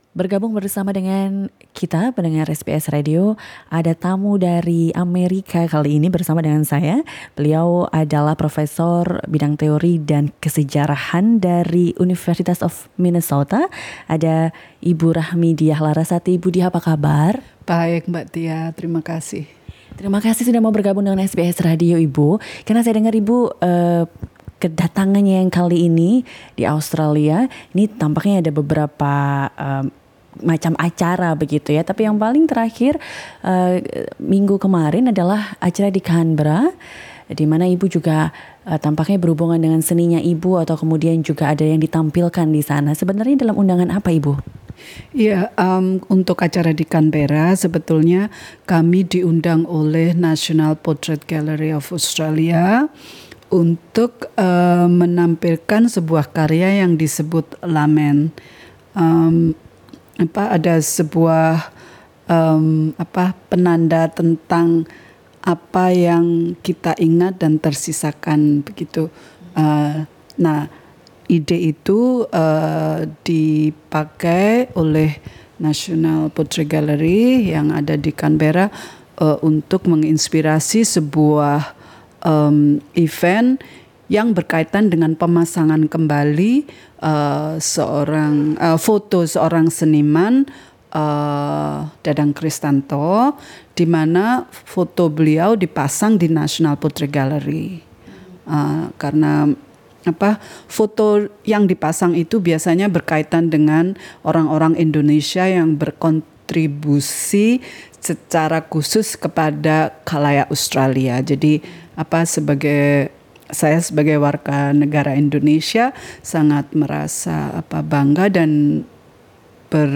Dalam perbincangannya dengan SBS Radio kali ini, Beliau berbagi pandangannya antara lain tentang keterkaitan gerakan tari tradisional Indonesia dengan pergeseran situasi politik, pentingnya memahami kesejarahan tarian, hingga situasi Indonesia saat ini dalam kaitan dengan 20 tahun Reformasi dan satu tahun menjelang PEMILU.